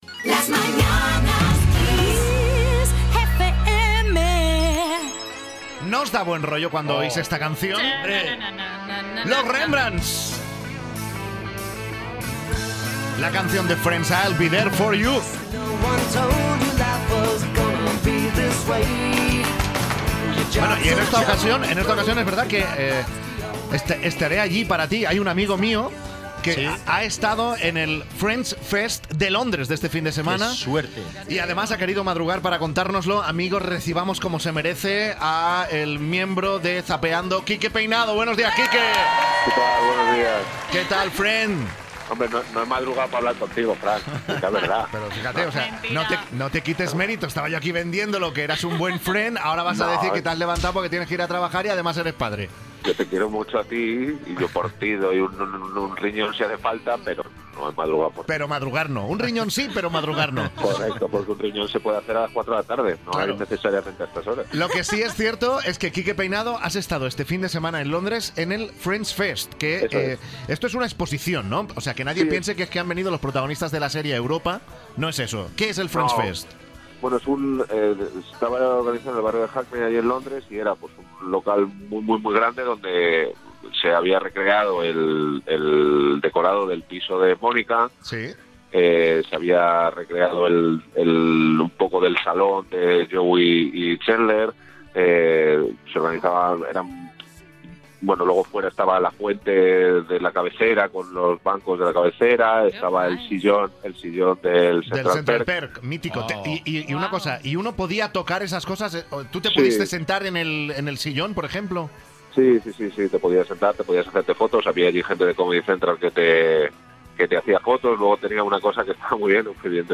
Frank Blanco habla con Quique Peinado sobre la estancia de este último en el "FriendsFest", serie favorita de ambos